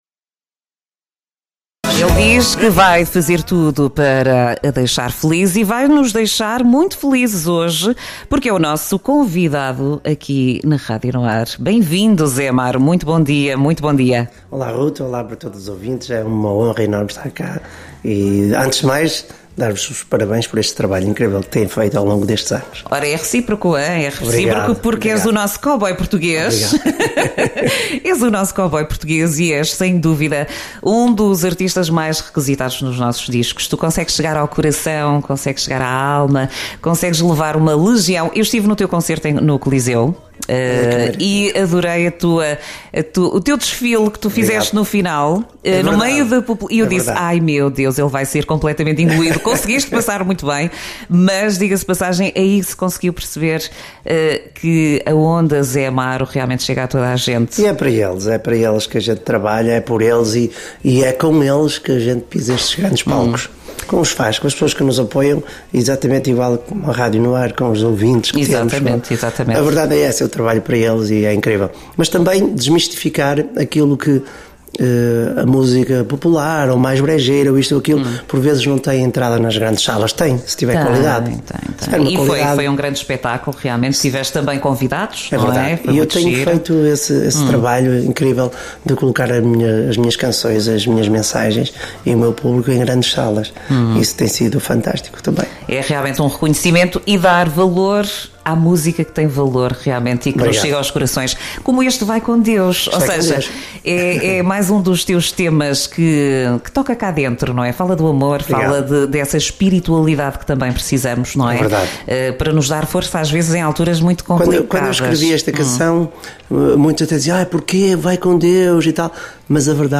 Entrevista de Zé Amaro em direto nas Manhãs NoAr